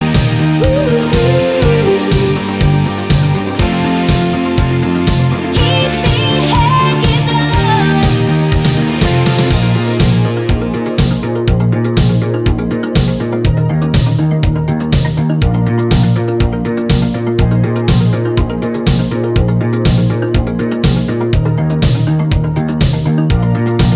Minus All Guitars Pop (1980s) 4:20 Buy £1.50